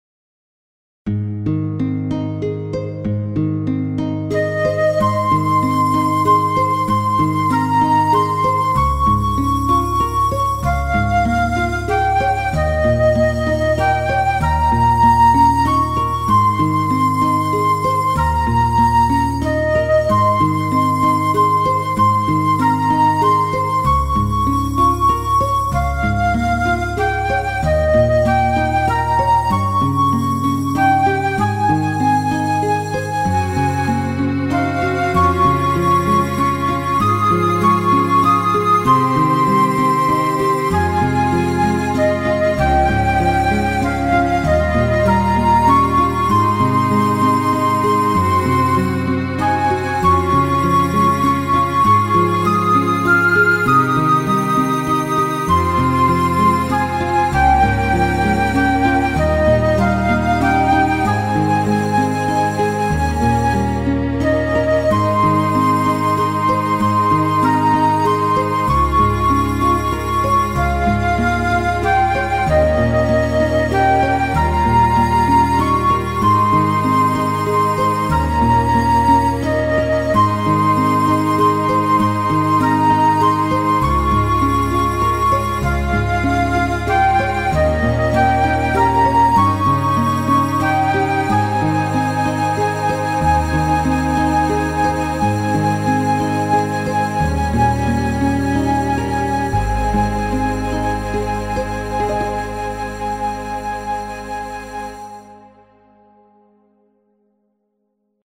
BGM
カントリーショート明るい穏やか